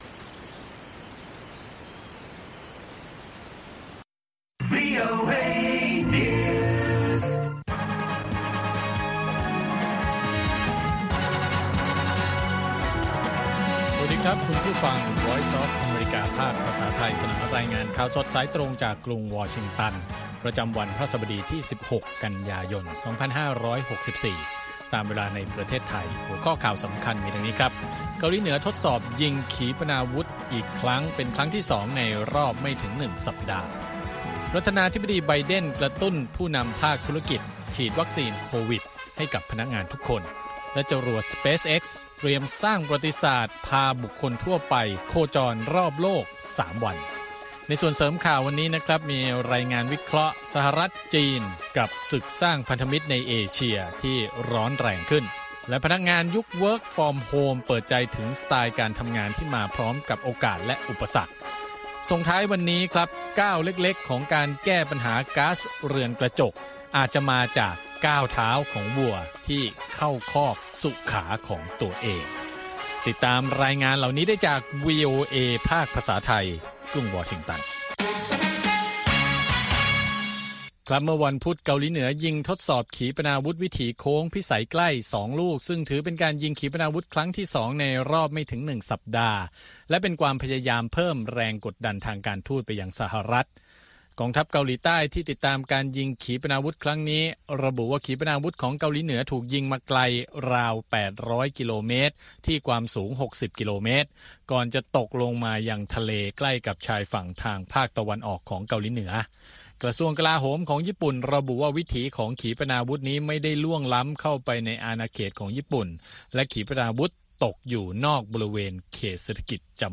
ข่าวสดสายตรงจากวีโอเอ ภาคภาษาไทย 8:30–9:00 น. ประจำวันพฤหัสบดีที่ 16 กันยายน 2564